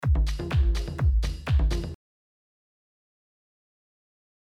Genre Tech House
Major
BPM 125